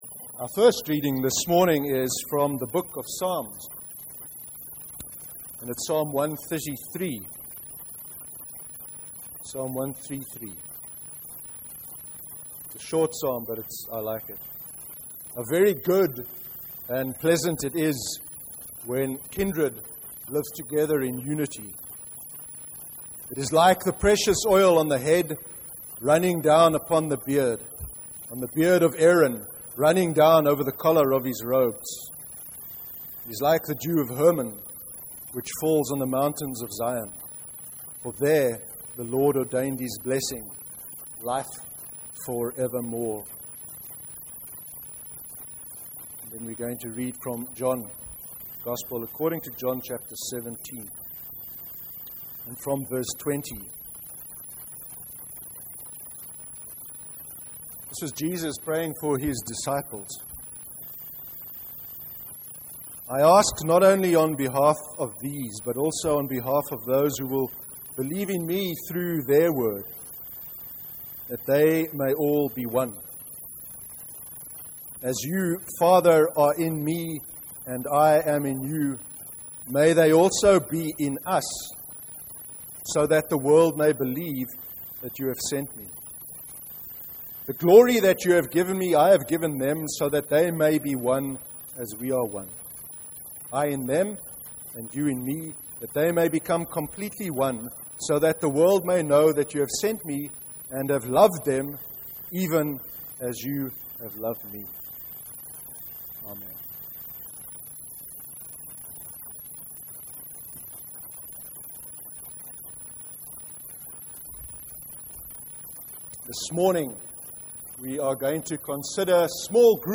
21/06/2015 sermon: Discipleship and small growth groups